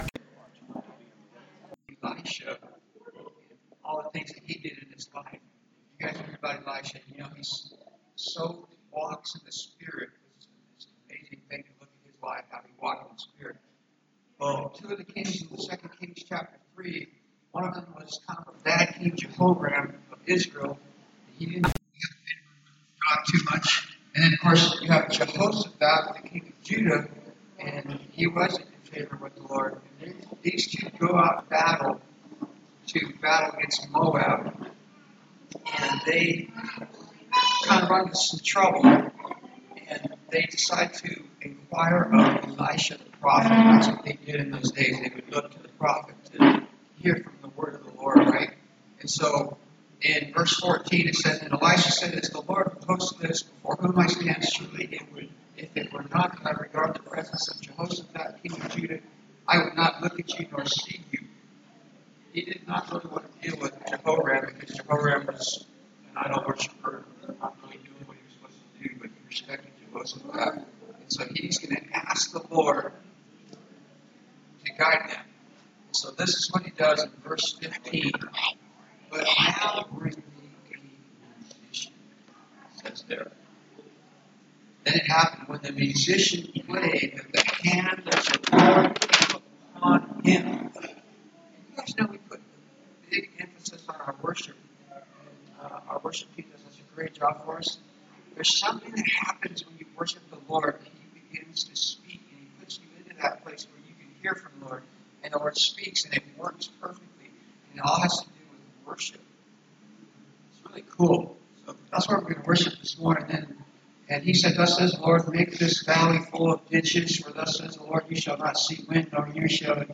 Acts 28:30-31 Service Type: Sunday Morning Service Ephesians 4 » Submit a Comment Cancel reply Your email address will not be published.